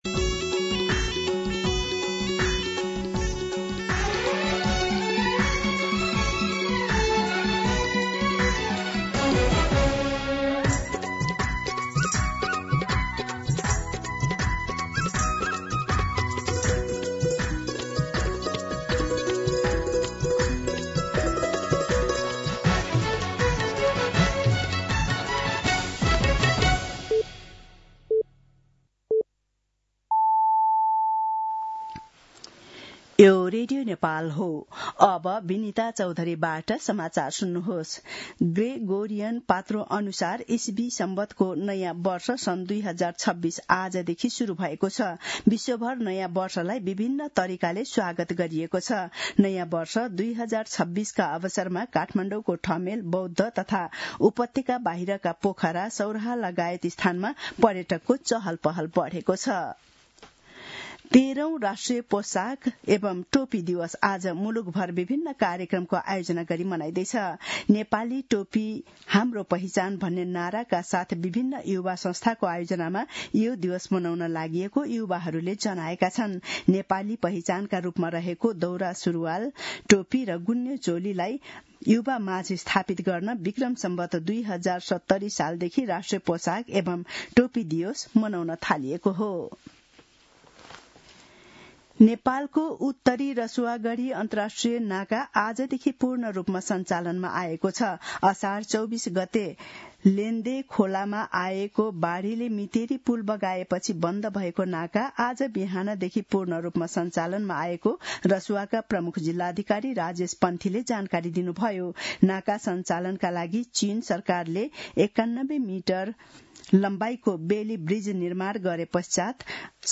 दिउँसो १ बजेको नेपाली समाचार : १७ पुष , २०८२